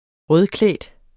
Udtale [ ˈʁœðˌklεˀd ]